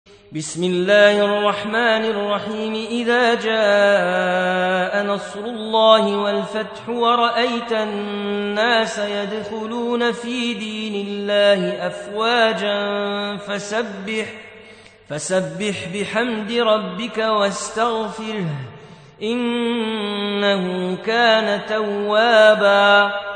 110. Surah An-Nasr سورة النصر Audio Quran Tarteel Recitation
Surah Sequence تتابع السورة Download Surah حمّل السورة Reciting Murattalah Audio for 110. Surah An-Nasr سورة النصر N.B *Surah Includes Al-Basmalah Reciters Sequents تتابع التلاوات Reciters Repeats تكرار التلاوات